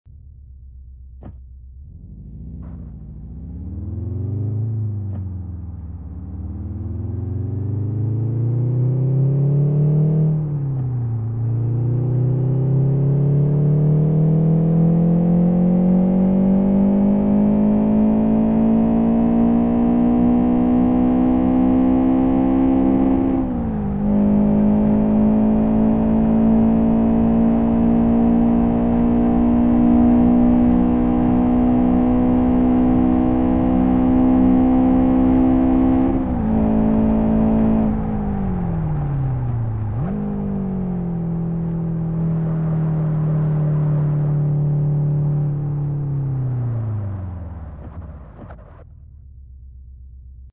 XFG_sound_v05.mp3 - 399.1 KB - 227 views